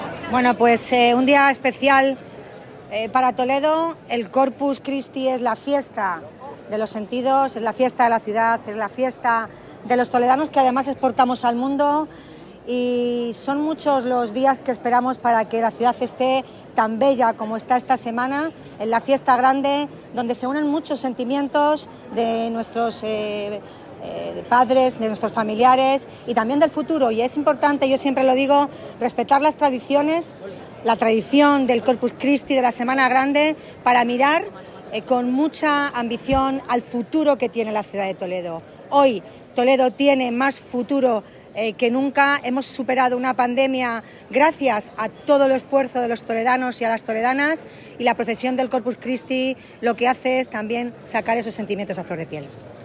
En una breve atención a los medios minutos antes de asistir a la misa en rito hispano-mozárabe presidida por el arzobispo Francisco Cerro en la catedral, Milagros Tolón ha afirmado que el Corpus es la Fiesta Grande de Toledo “que los toledanos exportamos al mundo” y ha destacado el esfuerzo, el trabajo y el cariño con que la ciudad se prepara para esta celebración.
Audio Milagros Tolón: